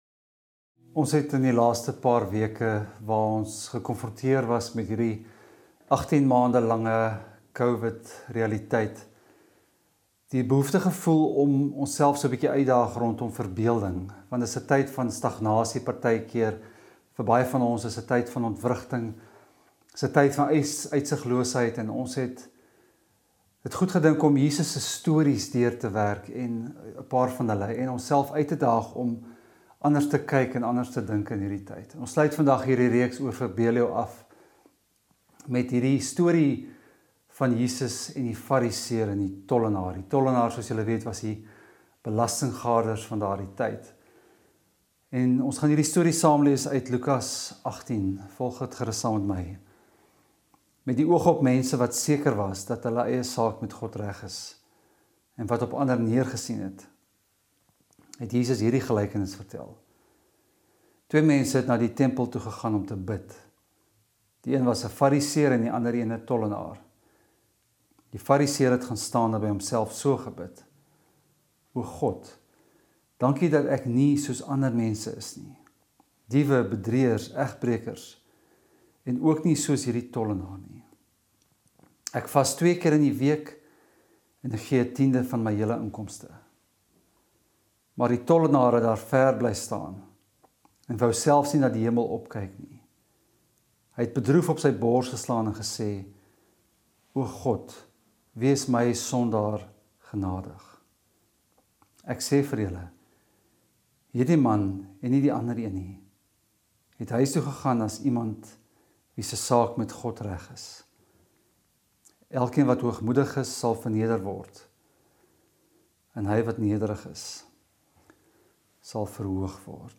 Preke